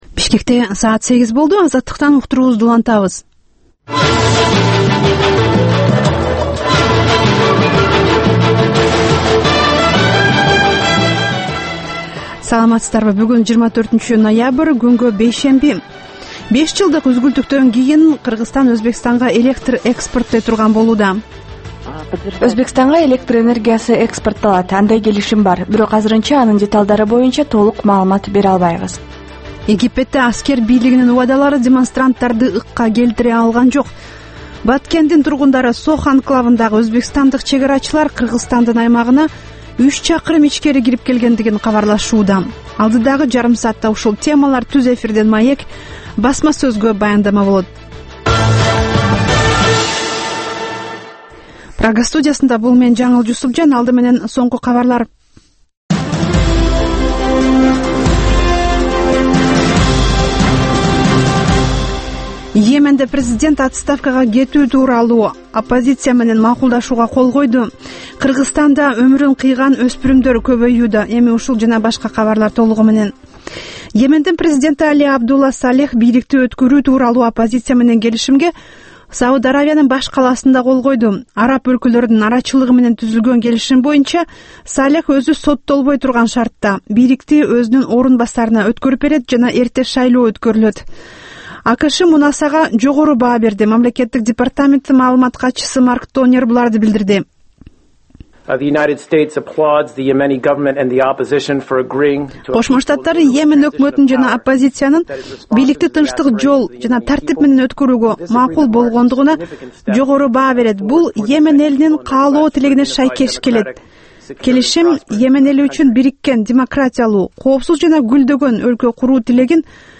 Таңкы 8деги кабарлар